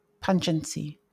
Pungency (/ˈpʌnənsi/